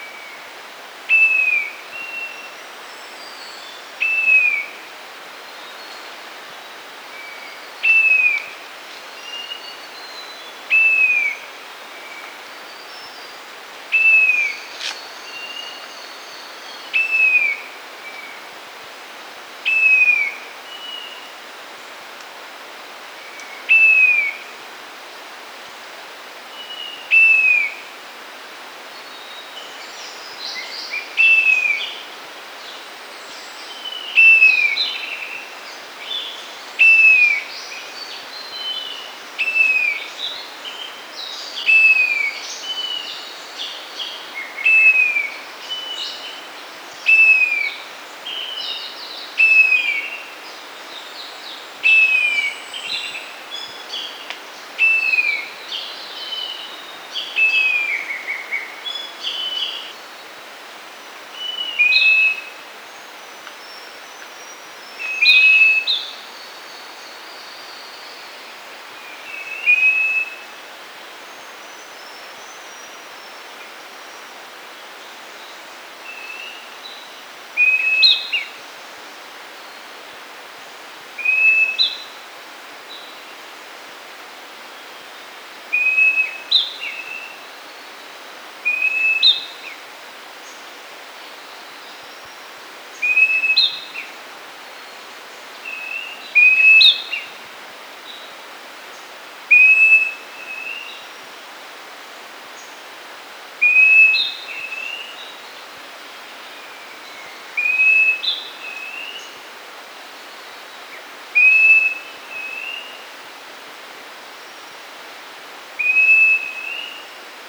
3. Red-bellied Woodpecker (Melanerpes carolinus)
Call: A rolling “churr” and loud “kwirr!”